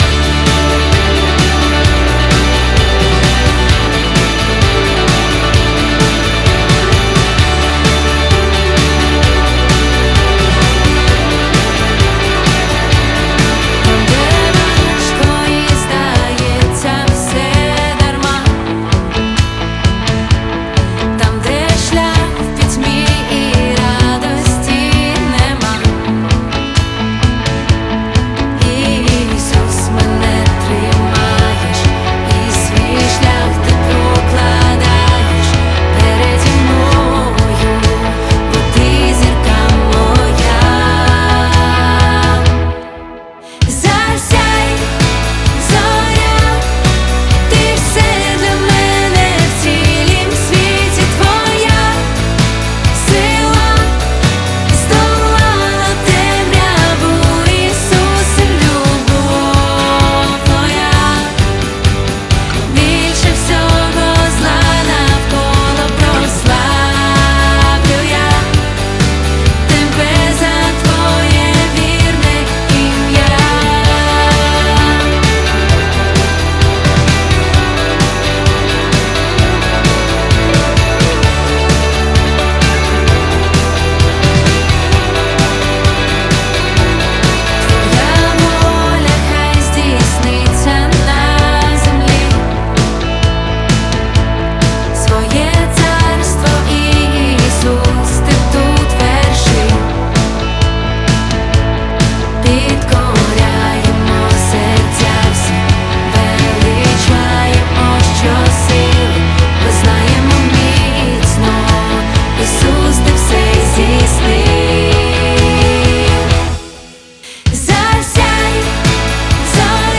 81 просмотр 159 прослушиваний 12 скачиваний BPM: 130